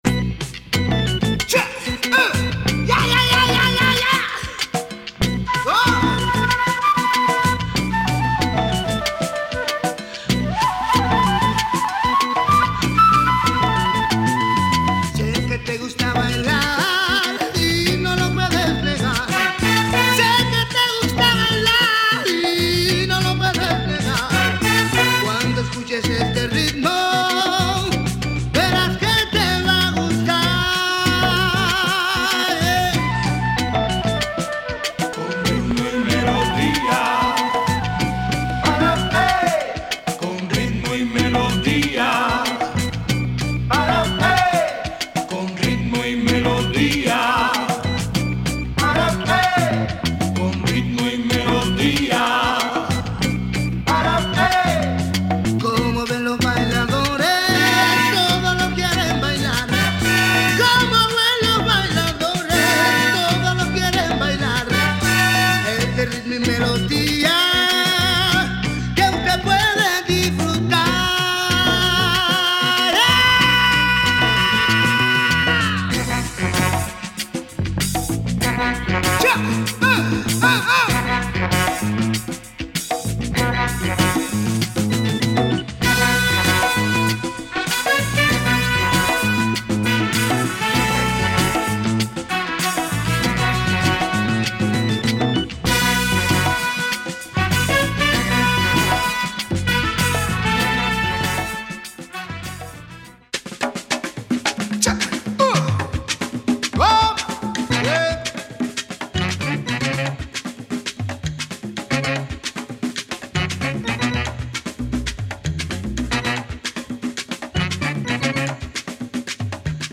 Heavy weight cuban funk